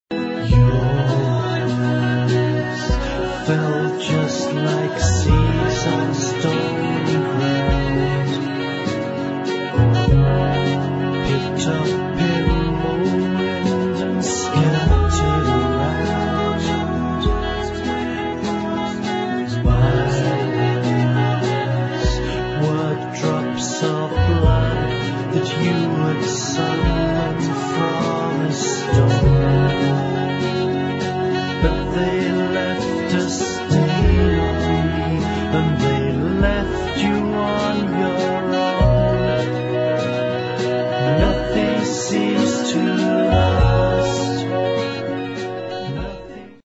[ ROCK / INDIE ]
トラッド感とサイケデリックなポップ・センスが融合した安心して聴ける一枚！